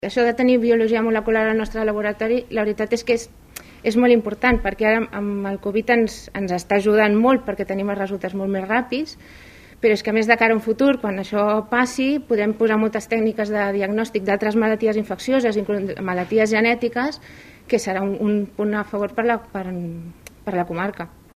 en una roda de premsa aquest dimecres